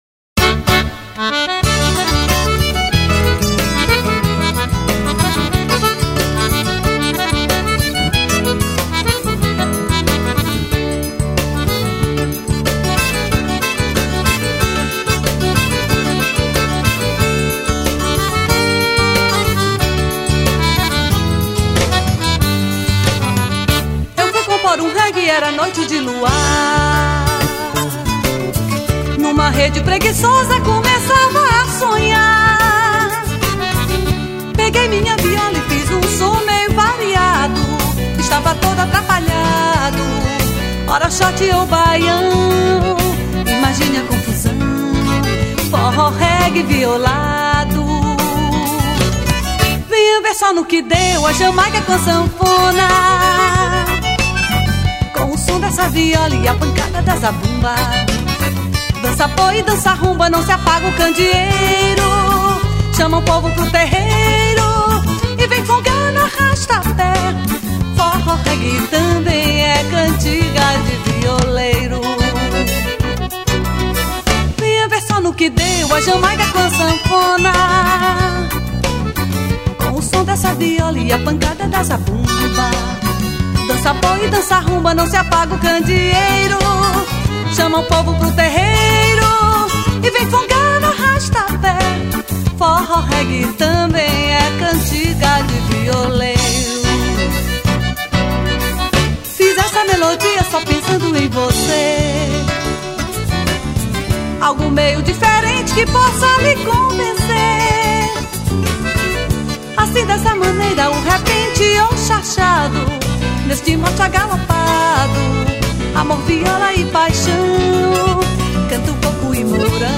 281   04:27:00   Faixa:     Forró pé de Serra
Viola, Violao Acústico 6
Baixo Elétrico 6
Bateria
Teclados, Acoordeon
Percussão